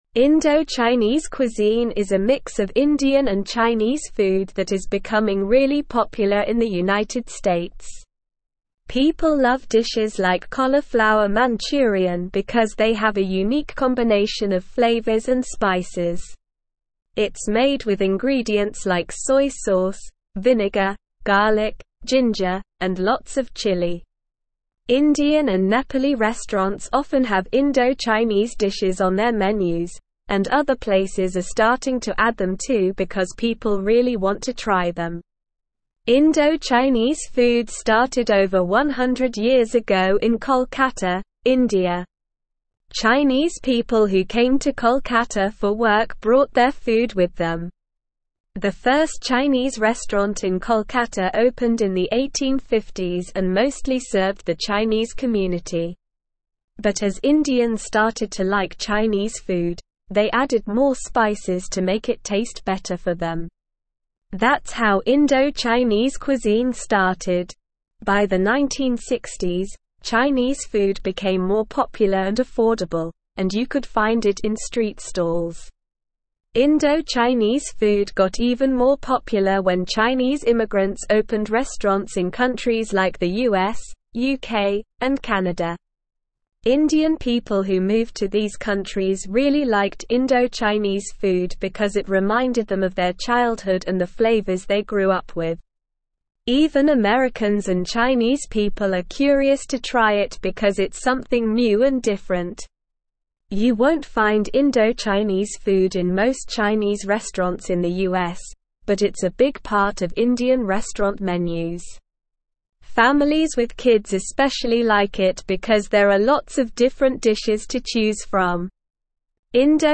Slow
English-Newsroom-Upper-Intermediate-SLOW-Reading-Indo-Chinese-cuisine-gaining-popularity-in-US-restaurants.mp3